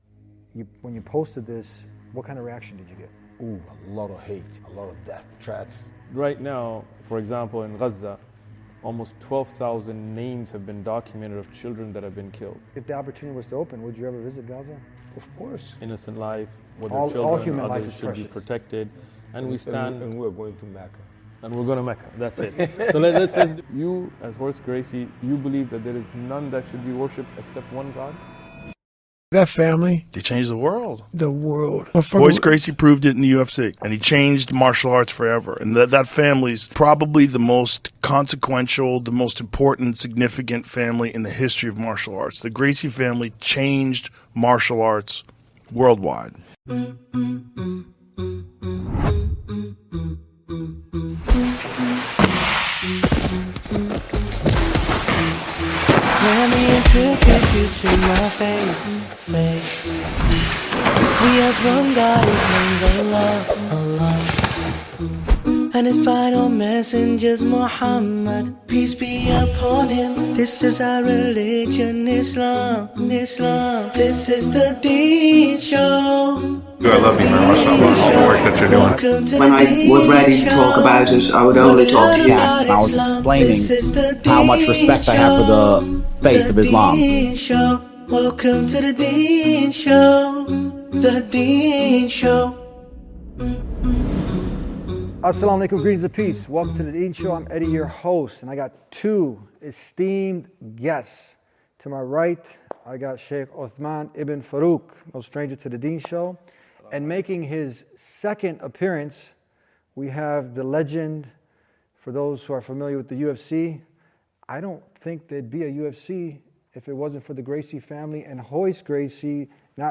In a historic moment at the Deen Center in Tampa, legendary UFC champion and founder of Brazilian Jiu-Jitsu’s global movement Royce Gracie sat down to clear up a misunderstanding that had generated death threats and hate from Muslims worldwide after a social media post about the conflict in Palestine. What began as a conversation about compassion for all innocent life ended with Royce Gracie taking his shahada and declaring his intention to visit Mecca.